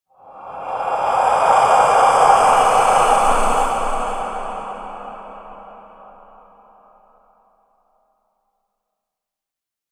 Ghostly Male Sigh – Eerie Horror Transition Sound Effect
Creepy-ghostly-ahh-sound-effect.mp3